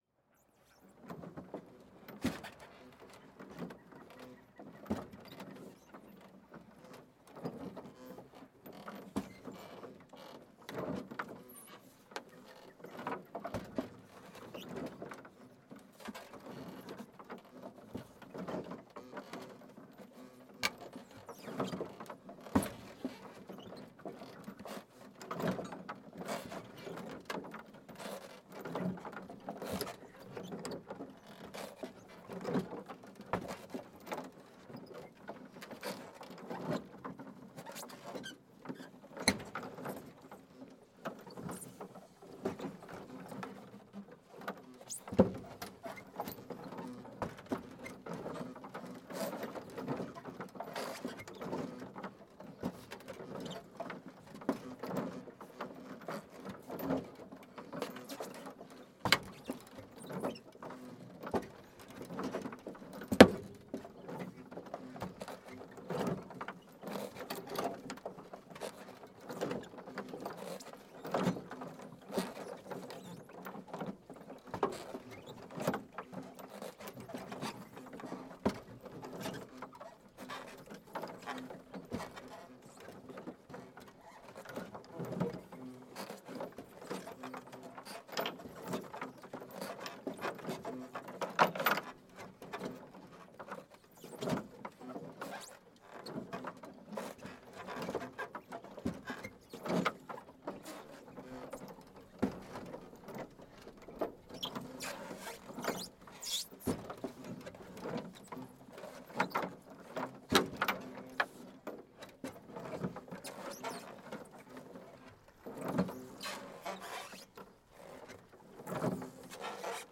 BOTE AMARRADO EN MUELLE DEL LAGO TITICACA, CRUJIR DE MADERA Y MOVIMIENTO DE SOGAS – Los sonidos del Perú
BOTE-AMARRADO-EN-MUELLE-DEL-LAGO-TITICACA-CRUJIR-DE-MADERA-Y-MOVIMIENTO-DE-SOGAS.mp3